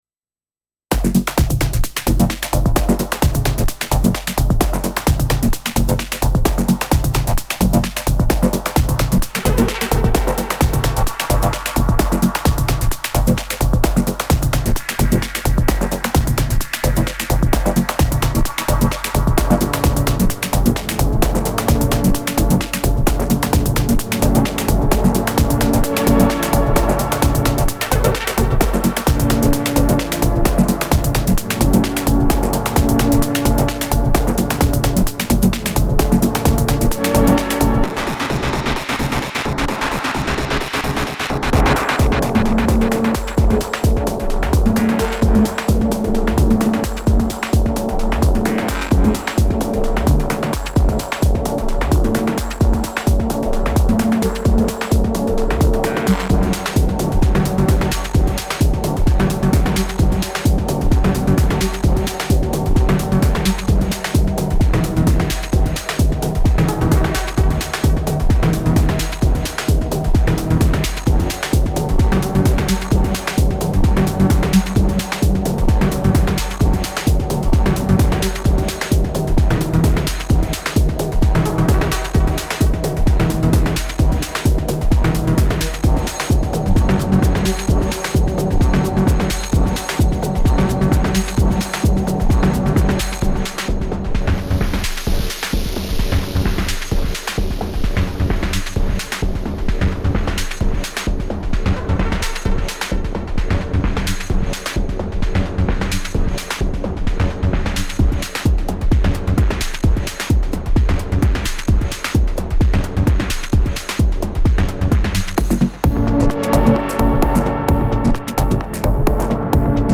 An eclectic collection, all recorded straight from the Syntakt.
More space techno.